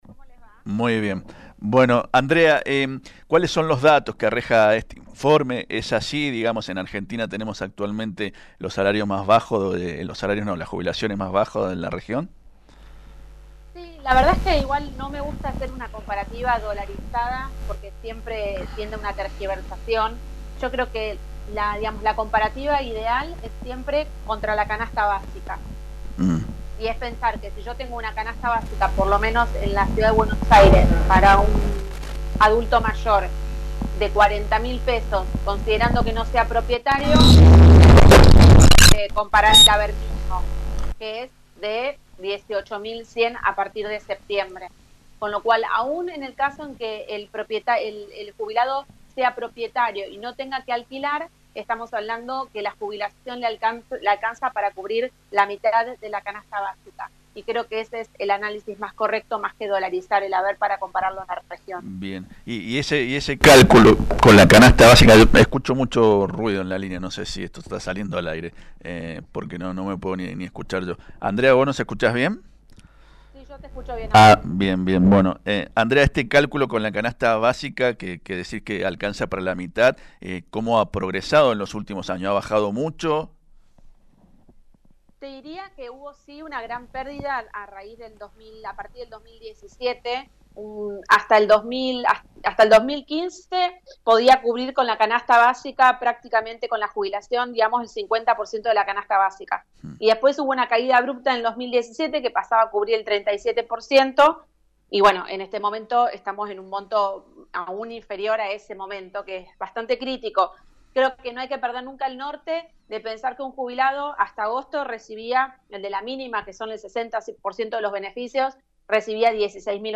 abogada previsionalista, en diálogo con Fm Vos (94.5).